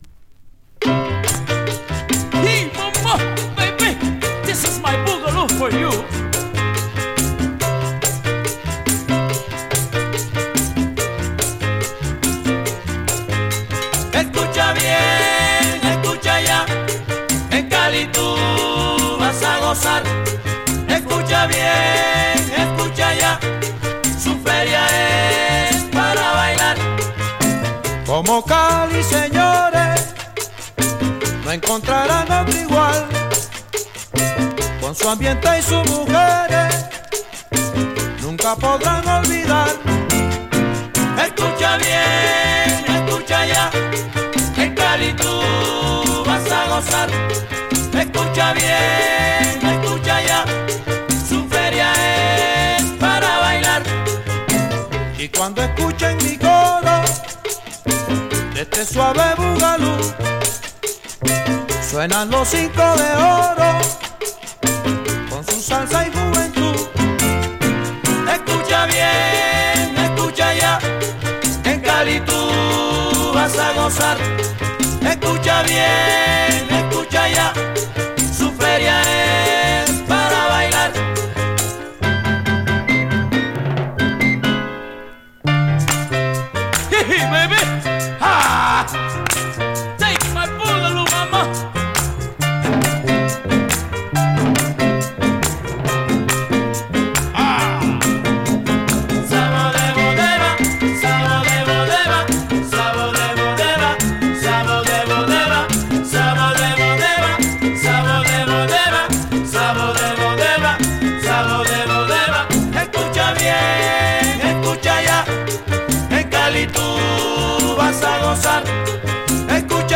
Boogaloo Colombiano